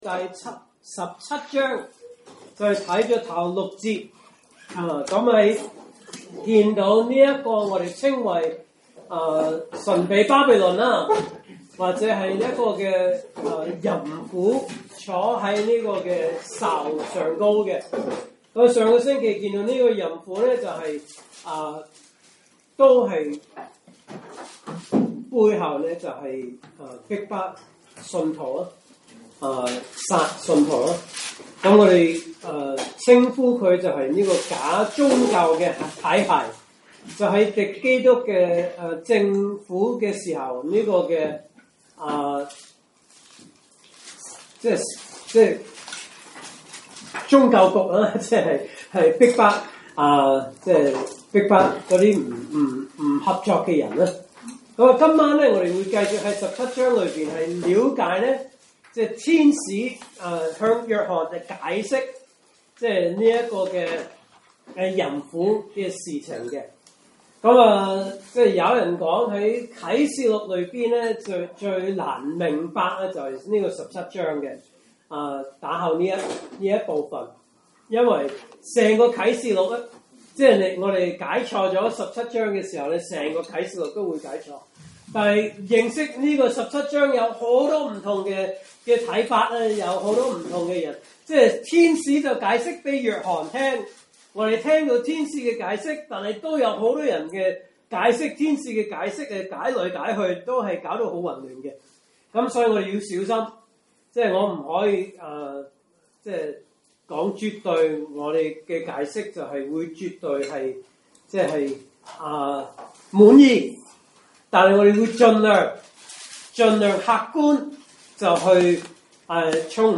來自講道系列 "查經班：啟示錄"